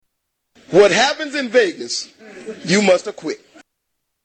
Tags: Television Kenan Thompson Kenan Thompson Impressions Kenan Thompson Clips SNL